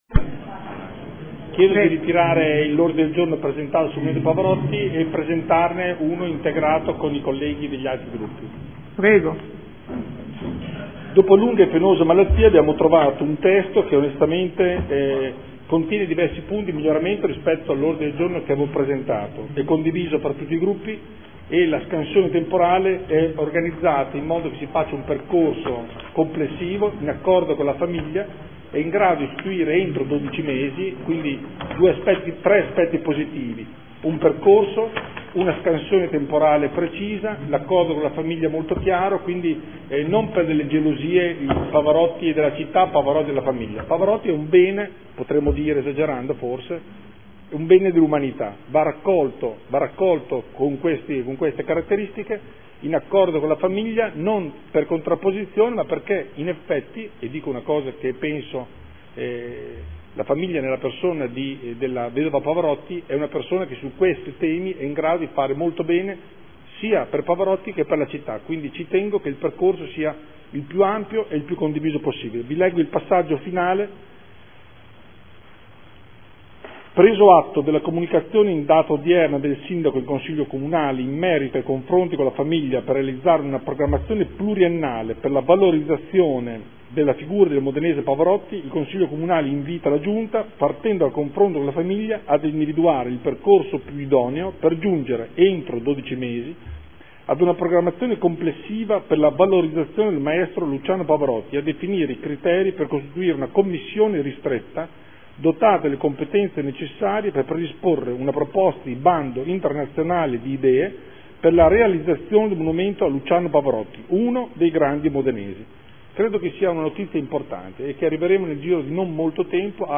Seduta del 11/09/2014 Ritira il suo ordine del giorno e ne presente uno concordato con gli altri gruppi.